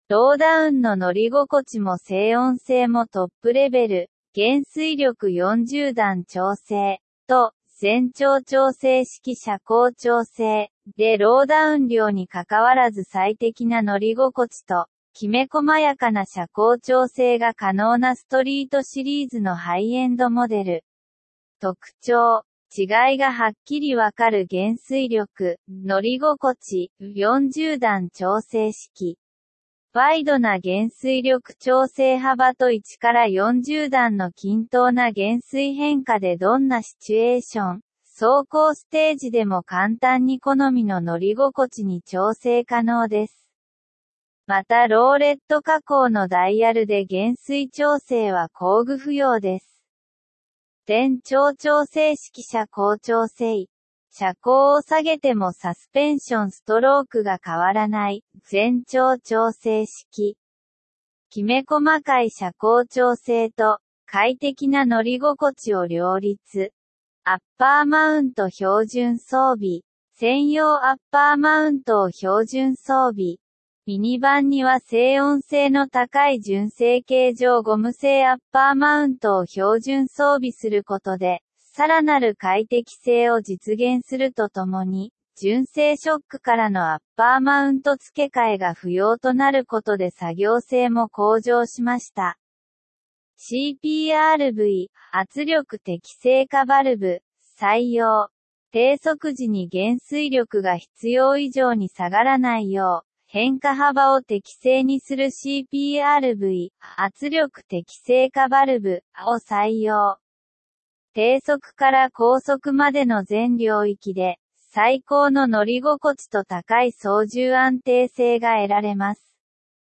音声で読み上げる